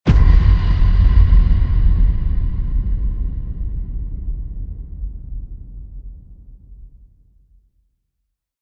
Sound Effects Library. Impact (CC BY)
sound-effects-library-impact_(3).mp3